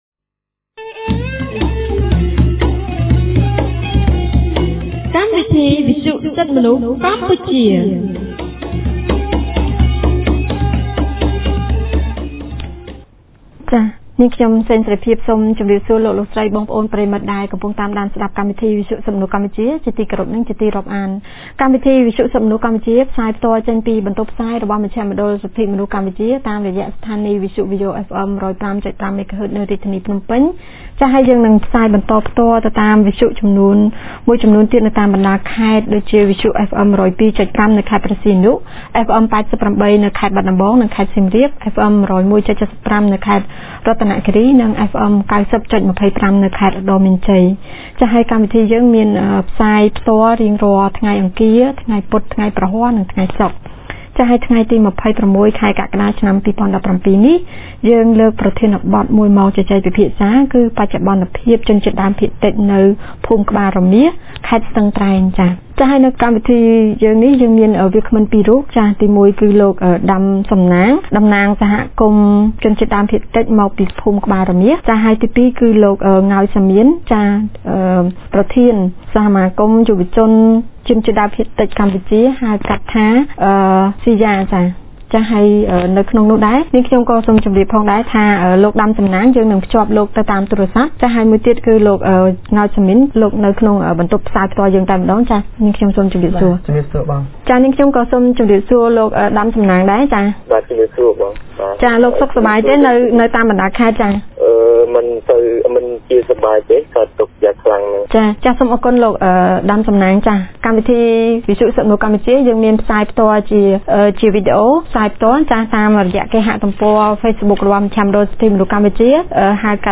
On Wednesday July 26, 2017 CCHR’s radio program held a talk show on topic “ Update on ethnic minorities in Kbal Romeas Village, Kbal Meas Commune, Sesan District, Stung Treng Province”.